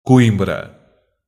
Coimbra (/kˈɪmbrə/, also US: /kuˈ-, ˈkwɪmbrə/,[1][2] UK: /ˈkɔɪmbrə/,[3] Portuguese: [kuˈĩbɾɐ]
or [ˈkwĩbɾɐ][4]), officially the City of Coimbra (Portuguese: Cidade de Coimbra), is a city and a municipality in Portugal.